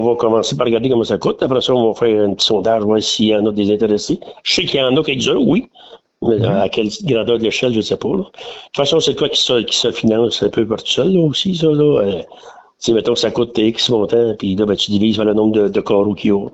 En entrevue, le maire, Mario Lefebvre, a mentionné qu’il y avait un projet qui est au stade embryonnaire de construire un columbarium dans le cimetière.